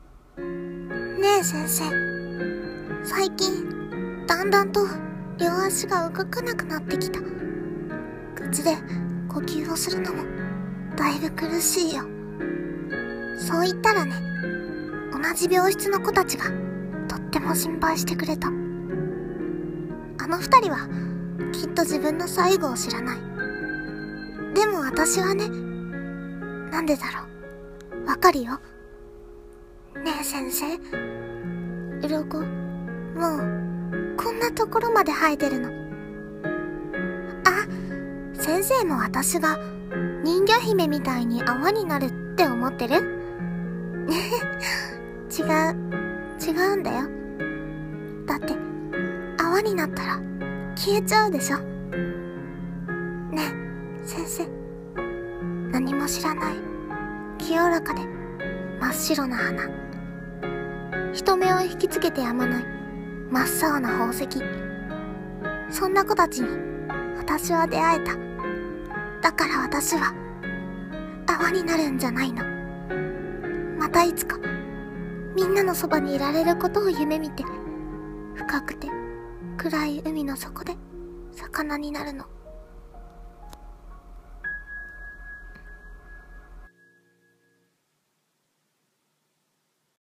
【一人声劇】深海魚